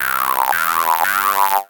• 1. ポルタメントなし・・・
このように普通に入力してしまうと、プラグイン側でポルタメント設定を有効にしていても音が分かれて聴こえます。